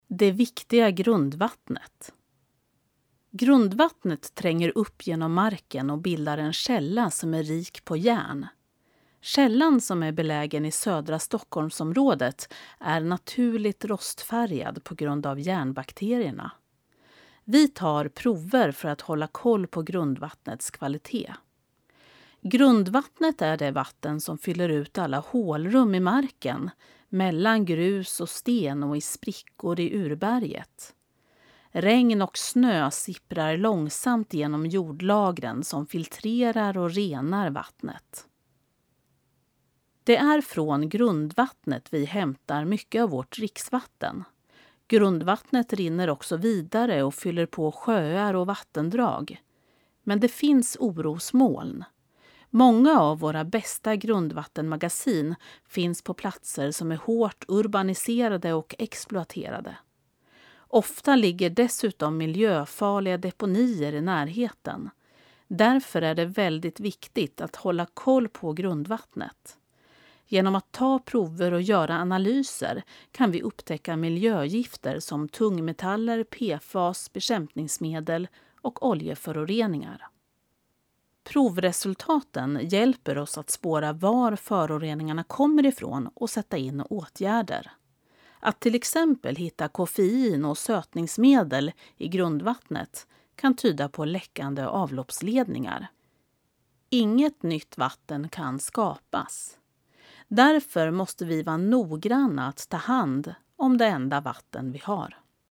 Klicka här för att lyssna till texten, inläst av professionell uppläsare